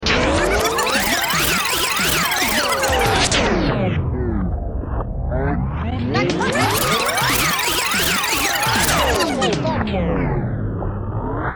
Отличного качества, без посторонних шумов.
354_peremotka.mp3